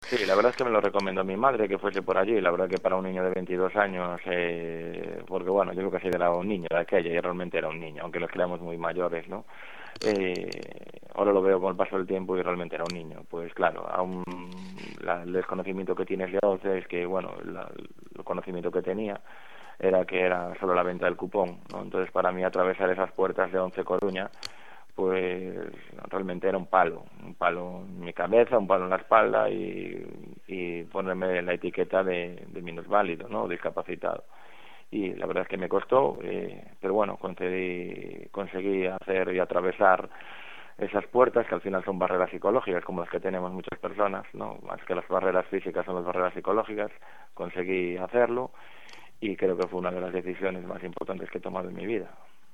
con sentida sinceridad formato MP3 audio(1,00 MB).